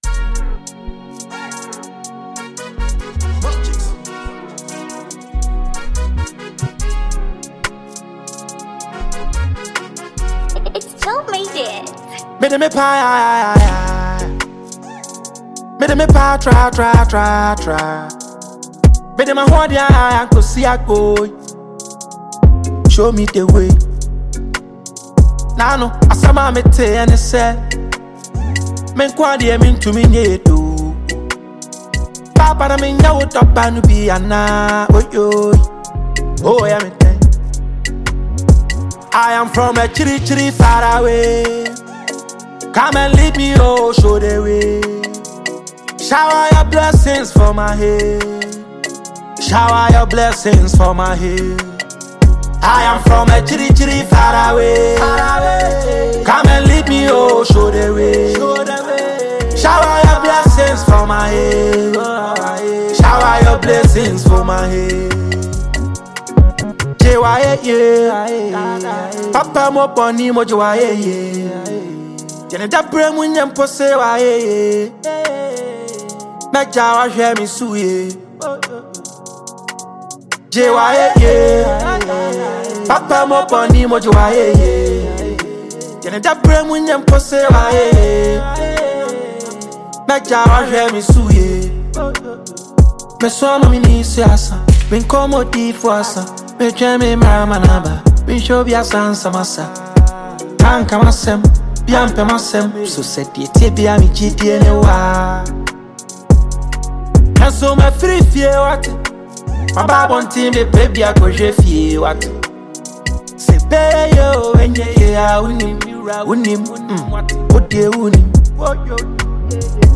Ghanaian rap sensation